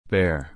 /pɛə(ɹ)/